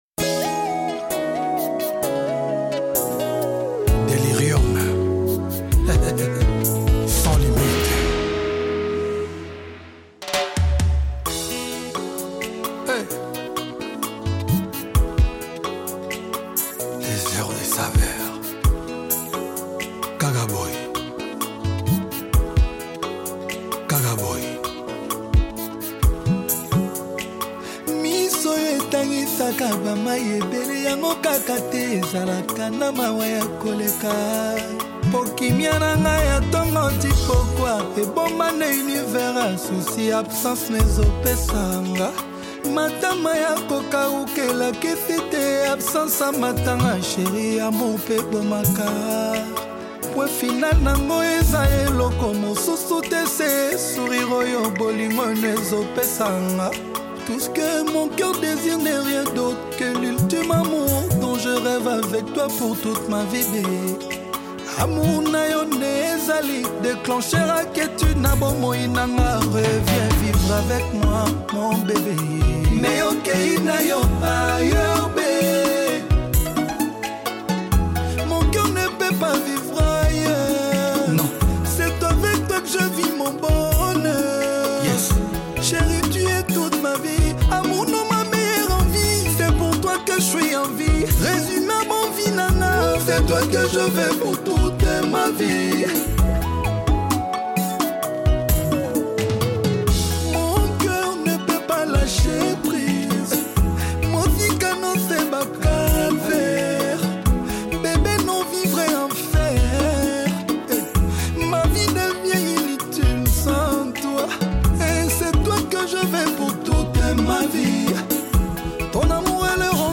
spirited rumba/Afro-urban single